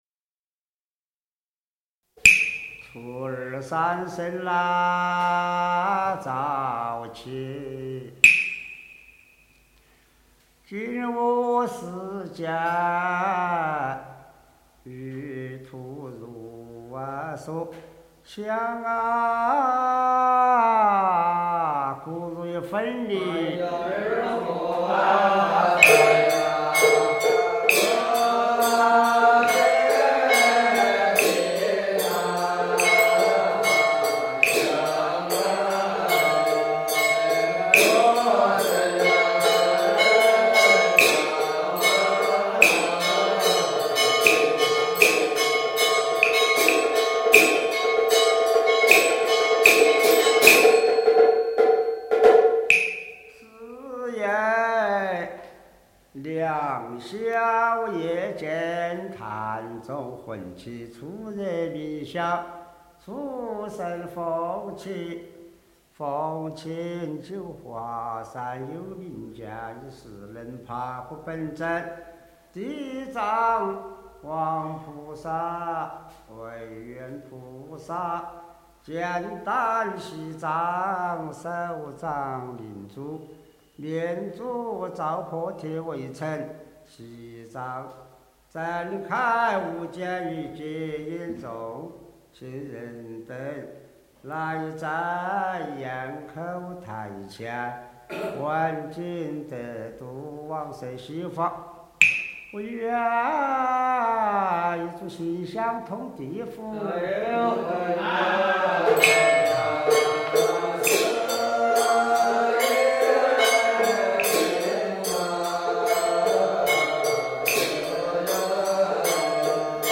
UQYK_LHS06.mp3 檔案下載 - 佛學多媒體資料庫 佛學多媒體資料庫 > 佛曲音樂 > 經典唱誦/法會佛事 > 瑜伽焰口 > 瑜伽焰口-羅漢寺 > UQYK_LHS06.mp3 > 檔案下載 Download 下載: UQYK_LHS06.mp3 ※MD5 檢查碼: ec0aa6199a1b9180a918c266bd1e4c2f (可用 WinMD5_v2.exe 檢查下載後檔案是否與原檔案相同)